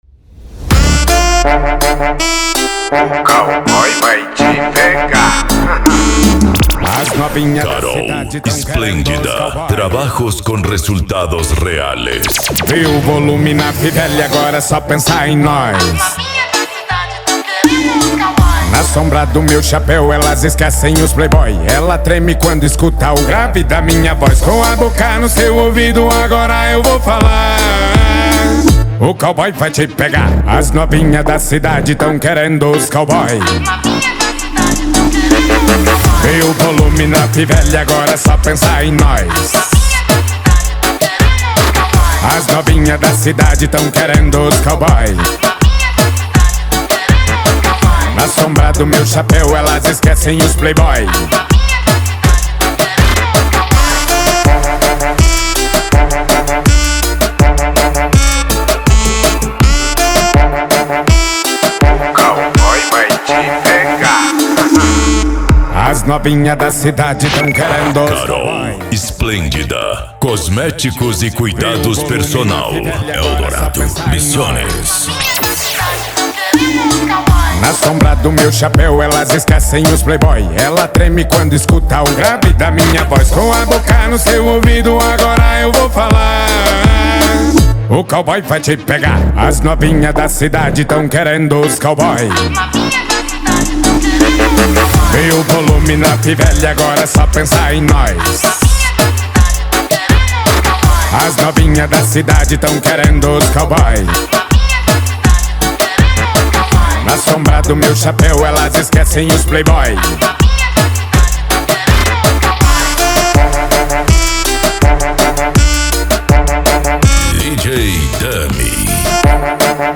Arrocha
Funk
Reggaeton